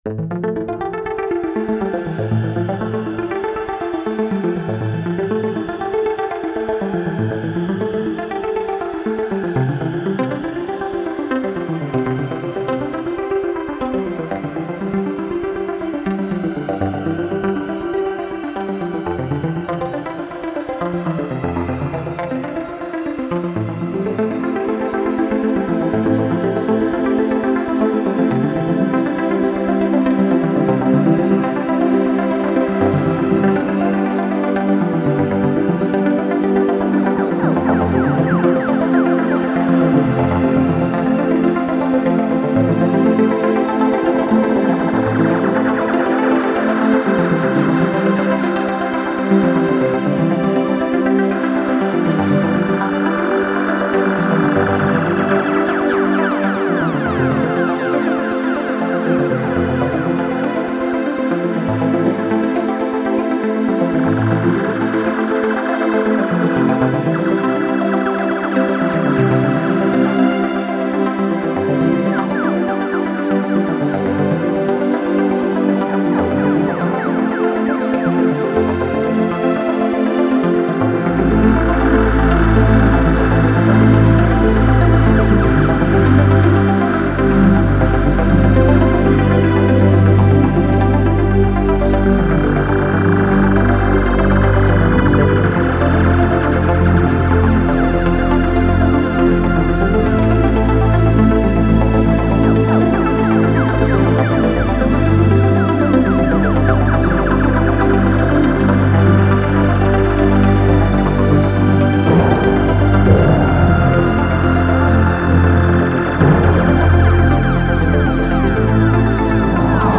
Genre: Electronic